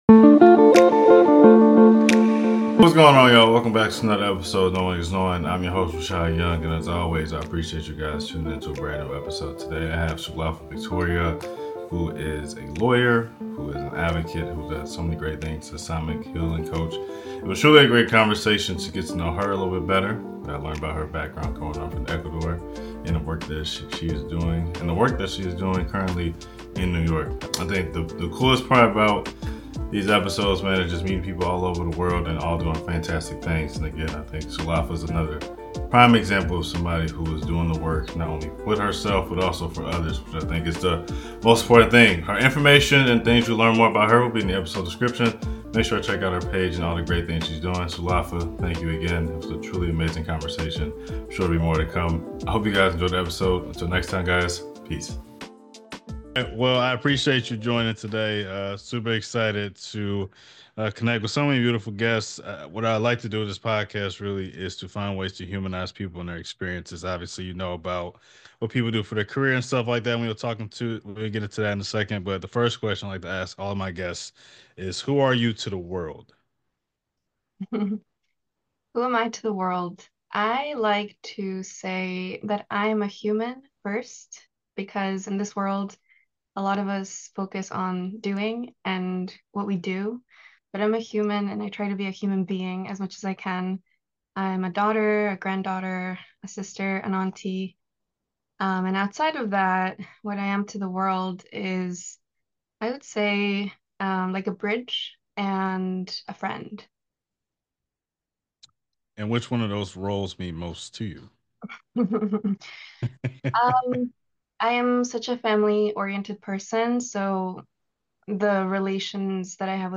in this heartfelt and inspiring conversation. Wel dive into the challenges of balancing a demanding engineering career with the joys and struggles of motherhood, while finding space to embrace creativity through poetry and self-expression.